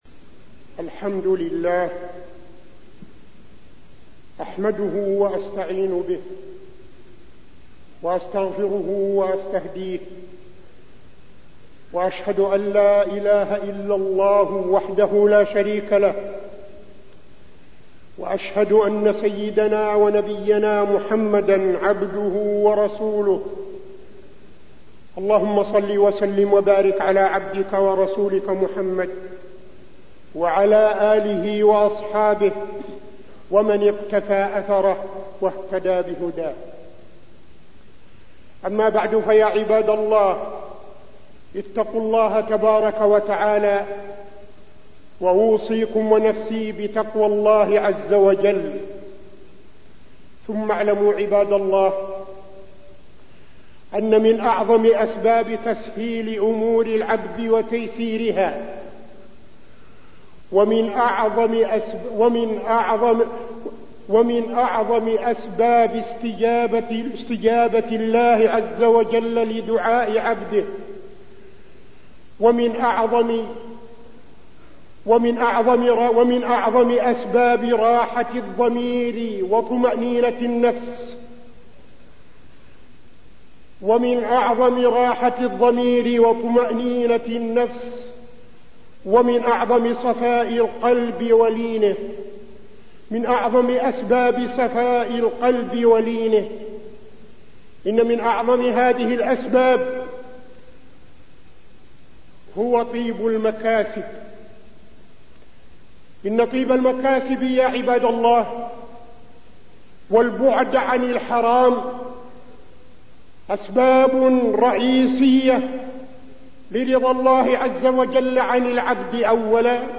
تاريخ النشر ٤ جمادى الأولى ١٤٠٥ هـ المكان: المسجد النبوي الشيخ: فضيلة الشيخ عبدالعزيز بن صالح فضيلة الشيخ عبدالعزيز بن صالح الكسب الحلال والبعد عن الربا